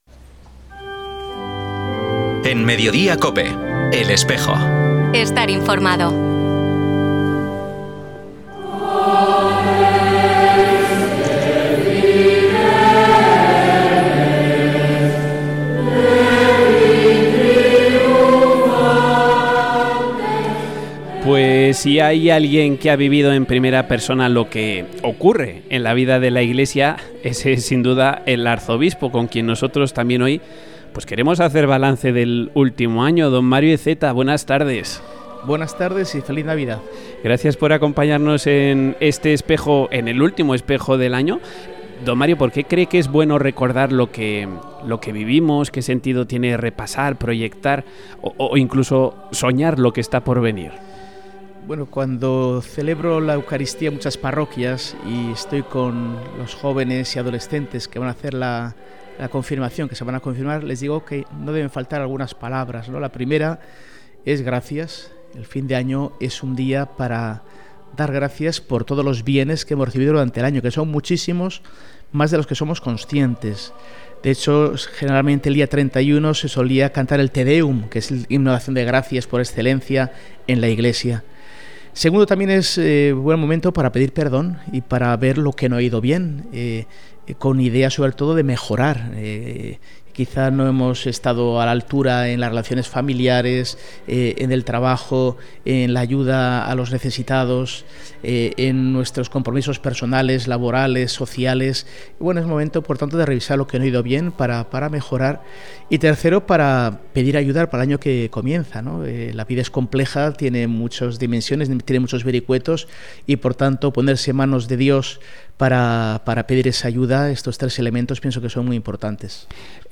entrevista-arzobispo.mp3